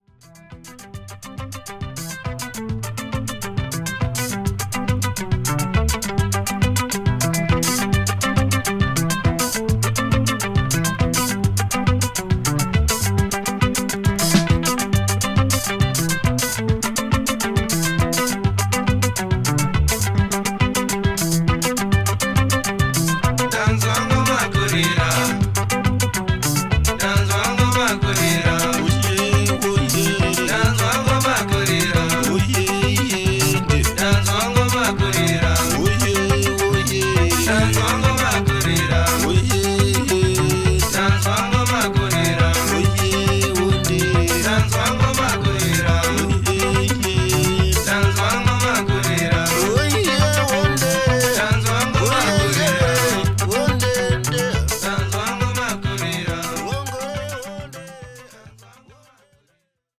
ショナ族の伝統音楽にリンガラやソウル、ロックの要素をミックスしたモダン・アフロ・サウンドの傑作です。